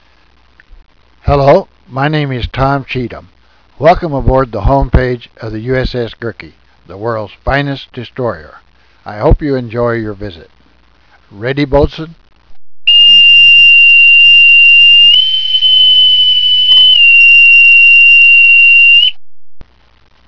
Please allow the duty Boatswain’s Mate to Pipe you aboard USS Gurke DD-783
Boatswain’s Pipe (218K,WAV)